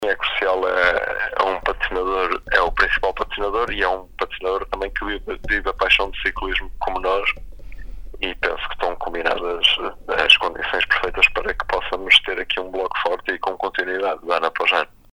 Em entrevista à Sintonia